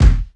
KICK_LIGHT.wav